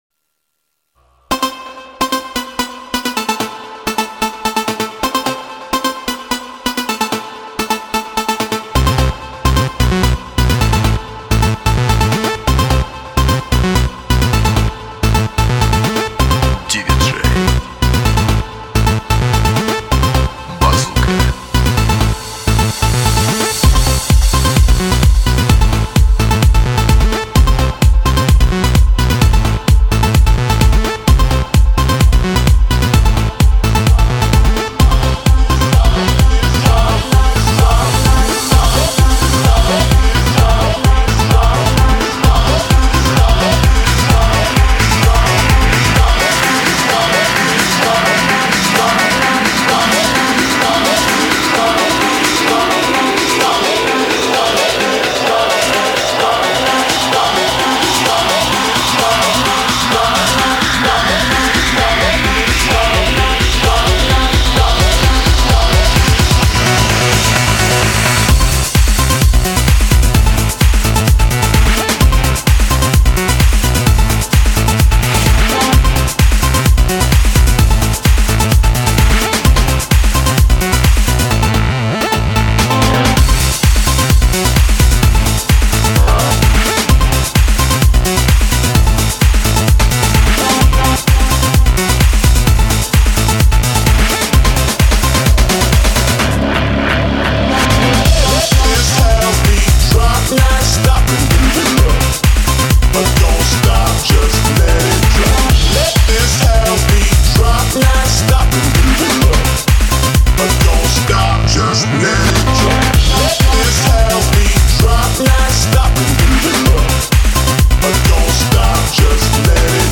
ДРУЗЬЯ !!! ну это (Electro house)